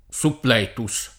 Suppl$tuS]) — cfr. additivo